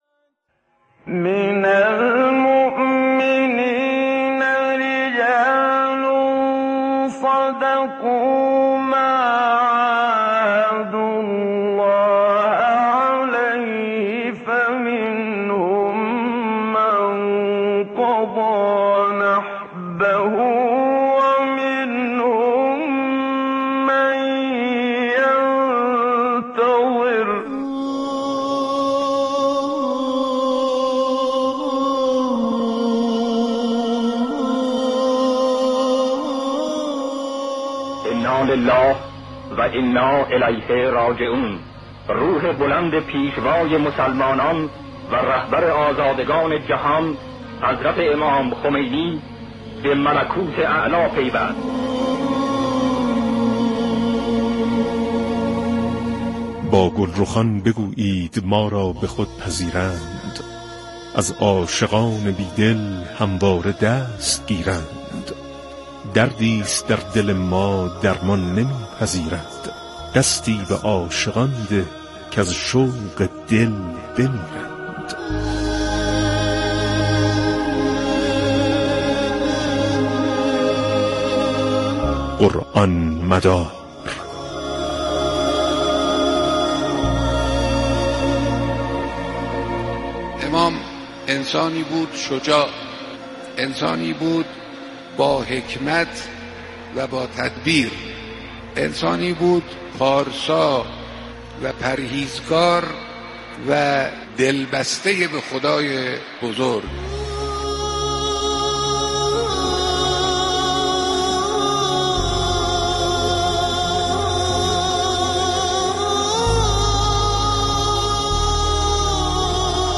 برنامه «قرآن‌مدار» عنوان مجموعه مستند رادیویی است که به تبیین سبک زندگی قرآنی و سیره سیاسی و اخلاقی امام خمینی(ره) می‌پردازد.